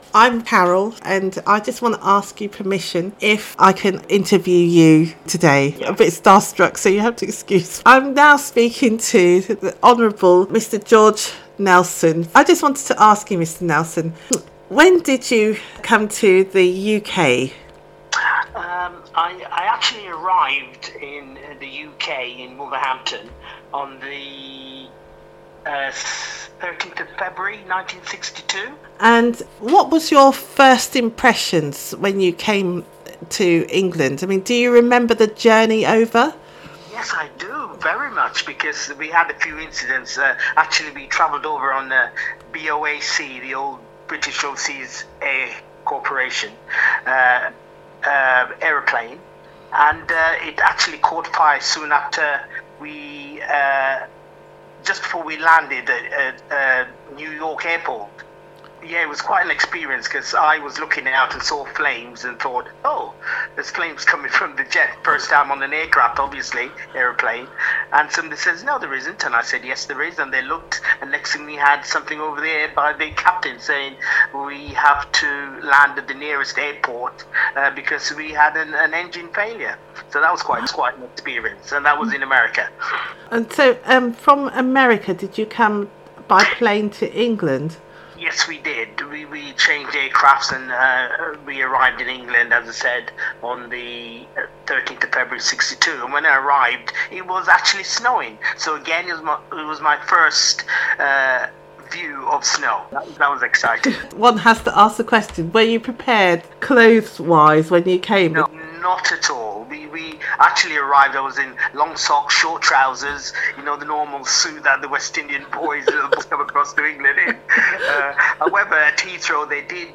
Oral / Unpublished Sources